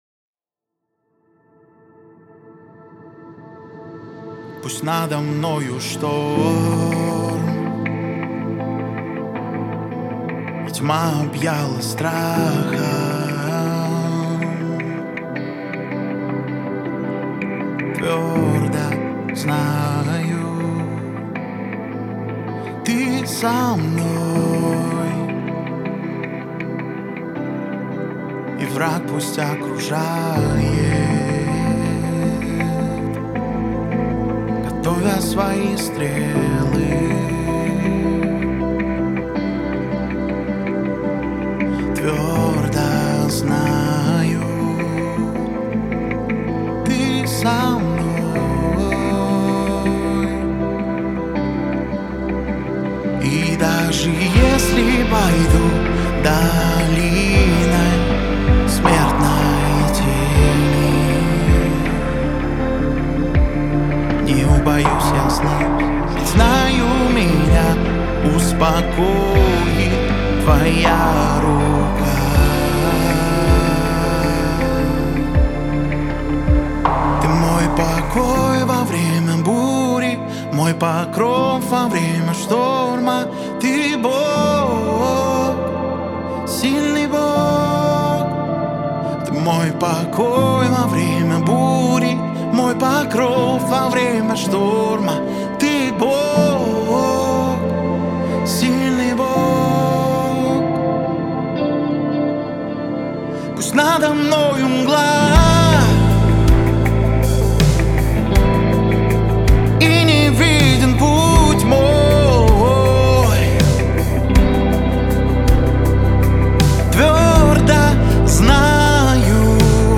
671 просмотр 562 прослушивания 82 скачивания BPM: 80